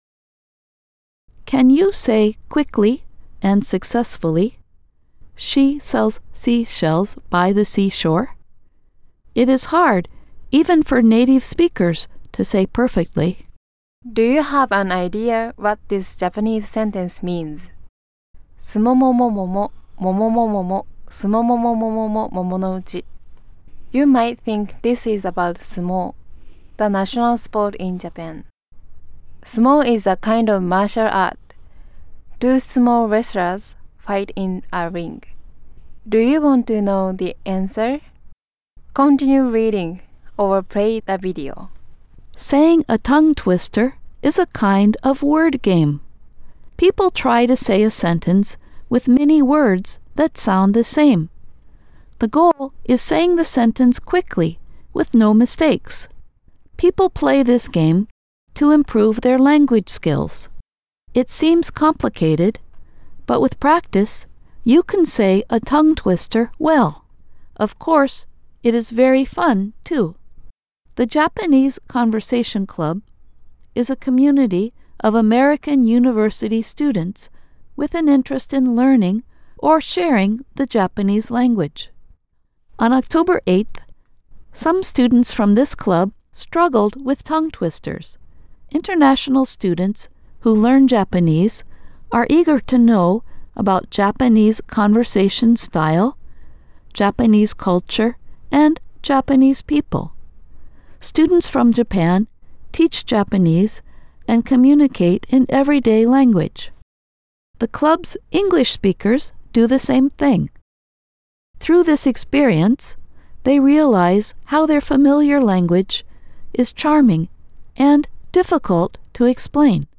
On October 8, some students from this club struggled with tongue-twisters.
The English speakers tried Japanese tongue-twisters.
The Japanese speakers tried English tongue-twisters.